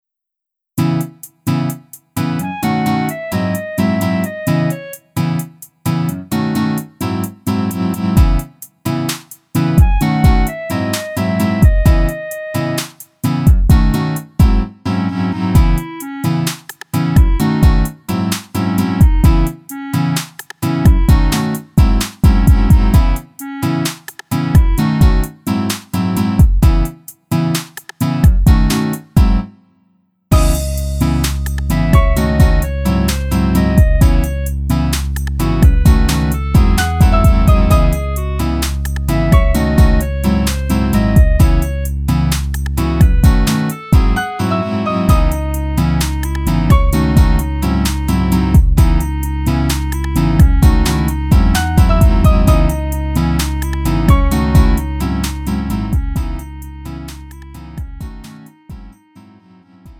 음정 원키 4:31
장르 가요 구분 Lite MR
Lite MR은 저렴한 가격에 간단한 연습이나 취미용으로 활용할 수 있는 가벼운 반주입니다.